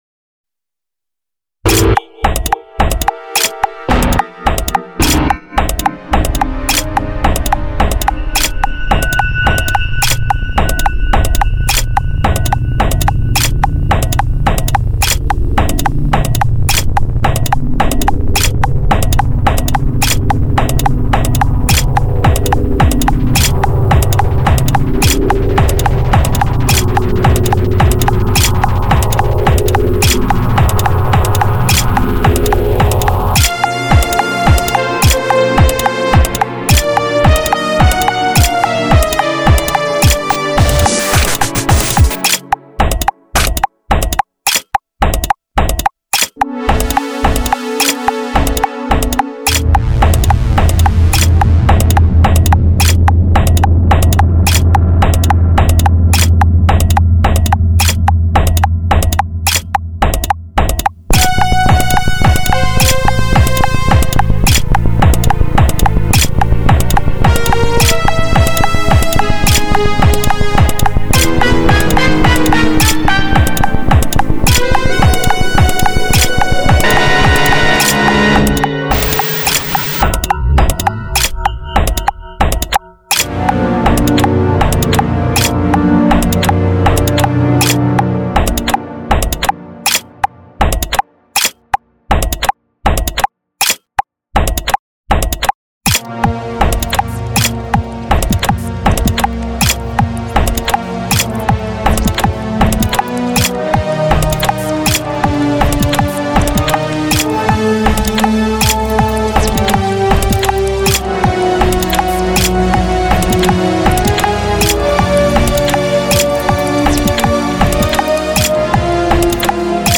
Backtrack with Click
EverythingFallsAway_BackTrack_With_Click.mp3